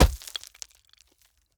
RockHitingGround_4.wav